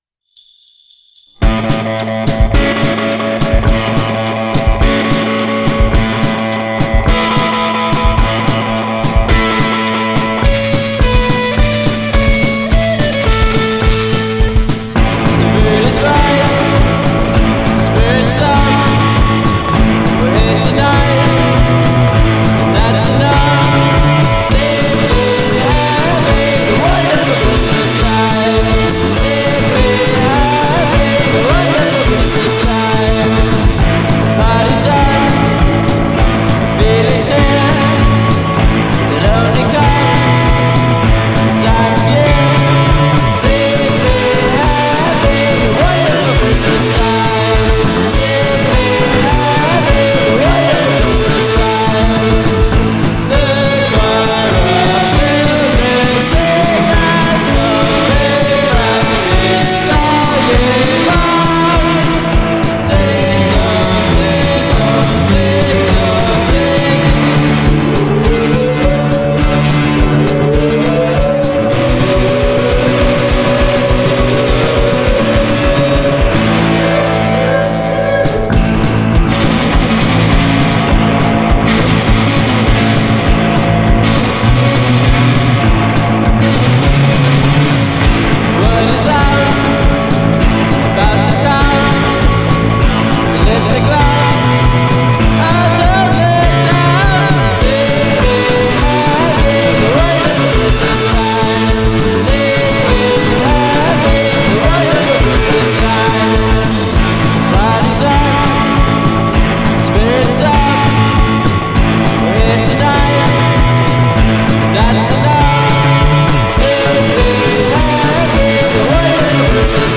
La sgangherata band